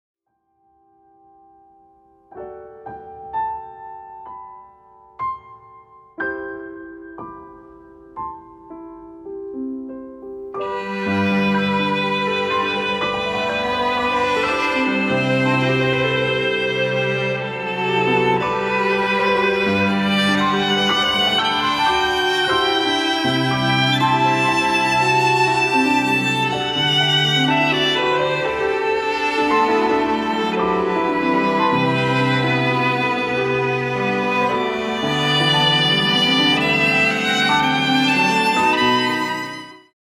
ヴァイオリン
レコーディングスタジオ : JEO